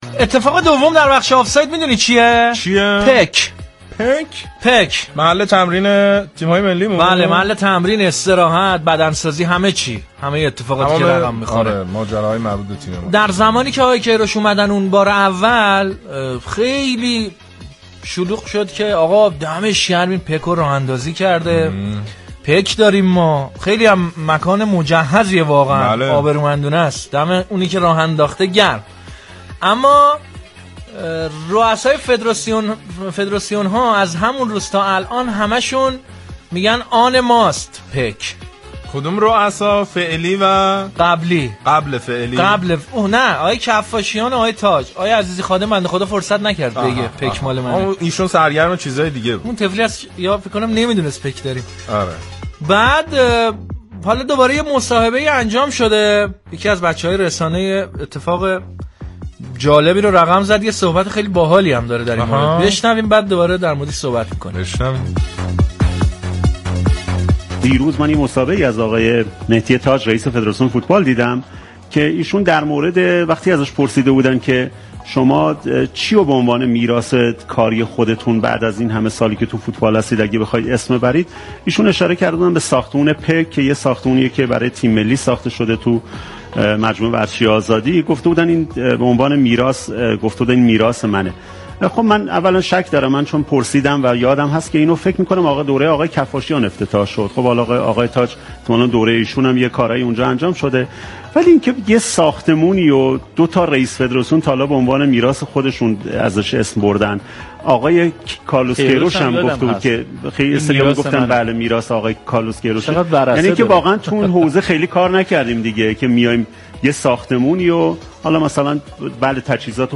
رادیو صبا در برنامه ورزشی «زیر طاقی» با بررسی مستندات از ماجرای تبدیل ایفمارك به پك پرده برداری كرد.